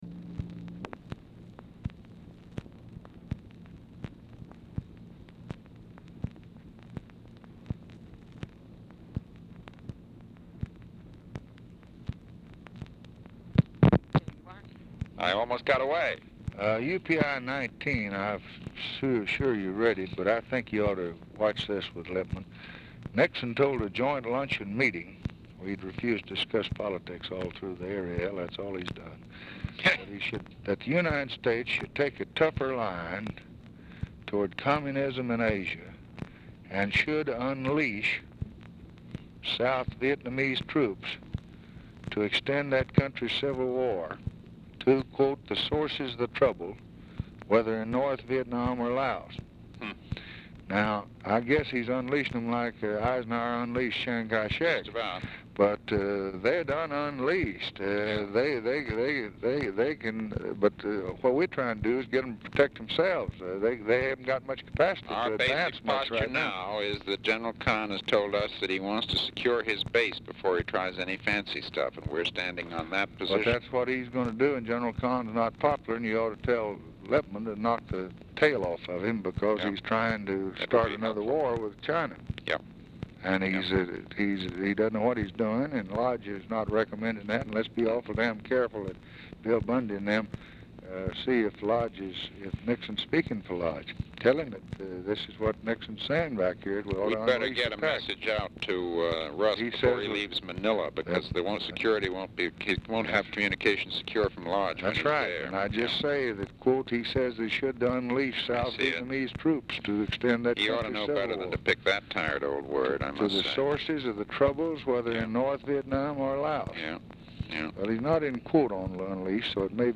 Telephone conversation # 3027, sound recording, LBJ and MCGEORGE BUNDY, 4/14/1964, 1:00PM | Discover LBJ
Format Dictation belt
Location Of Speaker 1 Oval Office or unknown location
Specific Item Type Telephone conversation